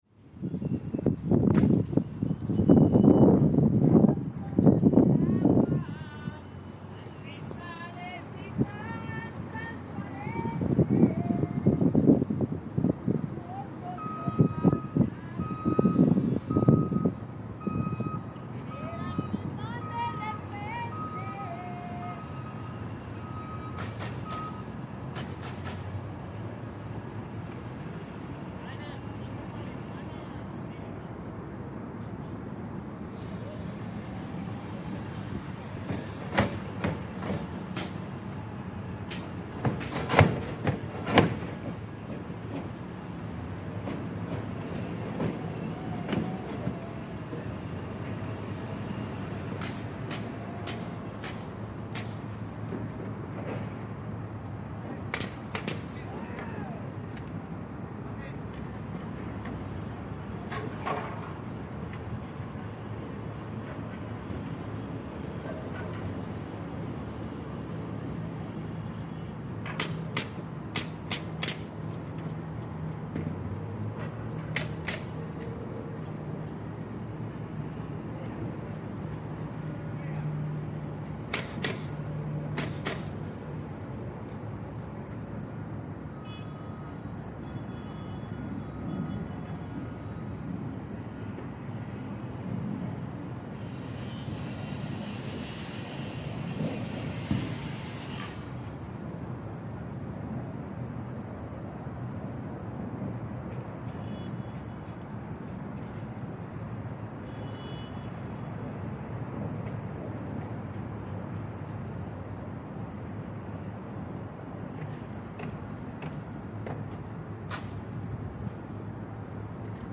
Recorded during the late summer months in a suburban town outside of Phoenix, AZ, a group of mostly migrant workers from Central and South American continue construction on a large-scale luxury apartment complex on a Saturday afternoon.
Standing on the adjacent lot, I observe numerous workers precariously standing atop a standing structure as they frame lumber underneath and around them while others move construction materials across the site. High pitched beeping sounds repeat that signal a truck is moving in reverse while a light breeze cuts the impact of the scorching desert heat when the temperature is at its highest in the day.